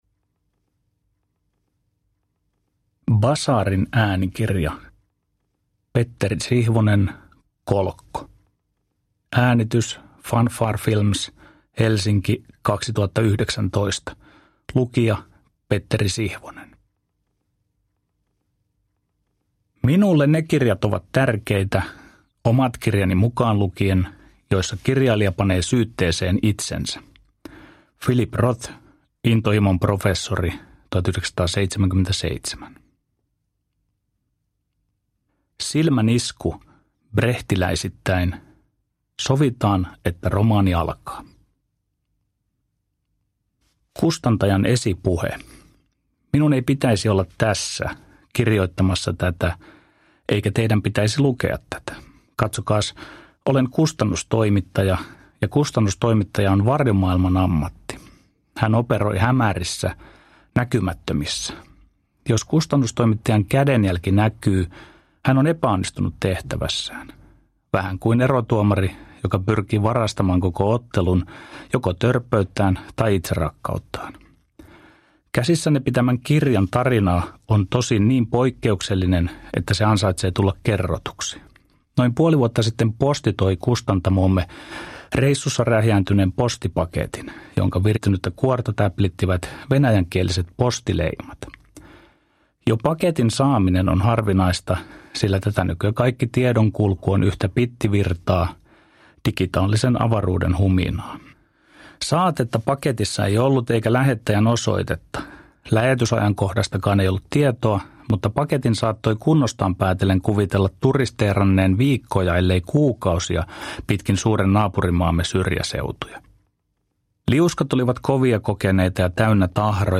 Kolkko – Ljudbok – Laddas ner